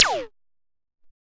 CD-ROOM/Assets/Audio/SFX/laser6.wav at main
laser6.wav